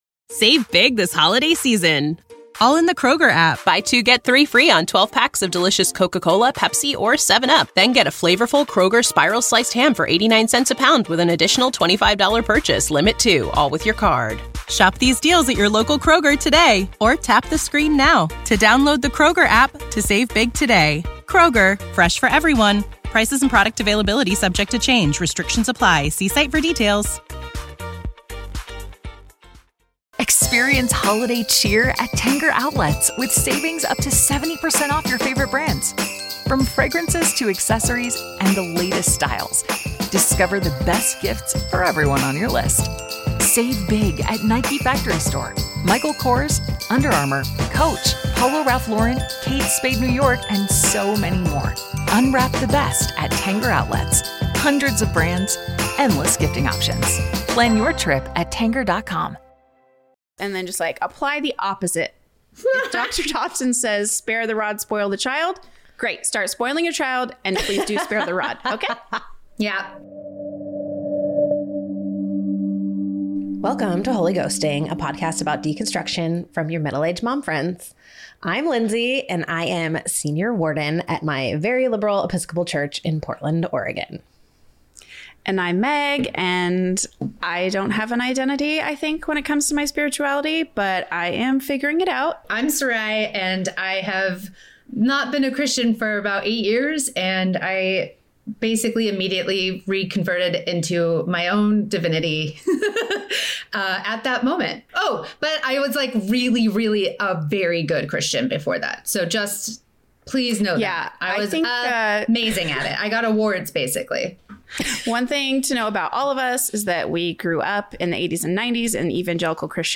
Join the conversation and hear how a handful of your fave middle-aged mom friends have been trying their hand at it so far.